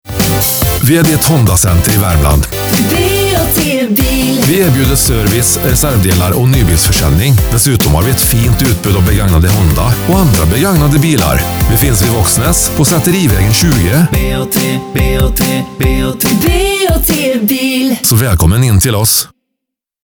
Ni har väl hört vår nya jingel? om inte klicka här!